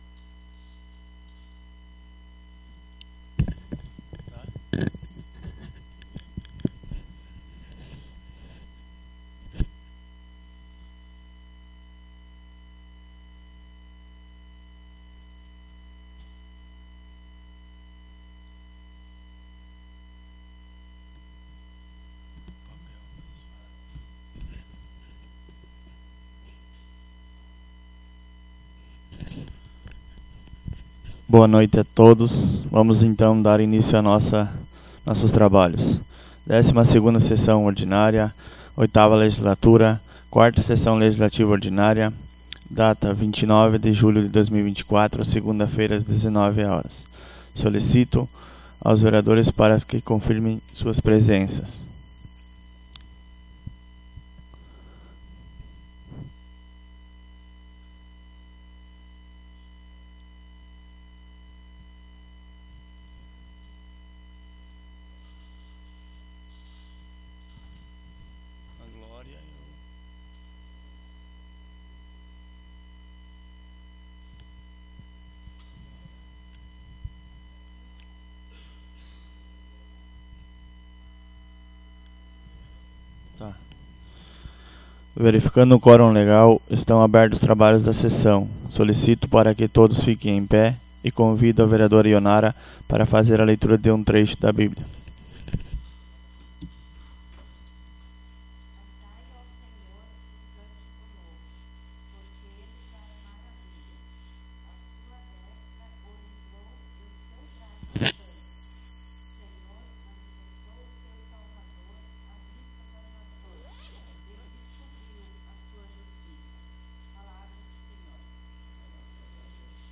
Audio da 12ª Sessão Ordinária 29.07.24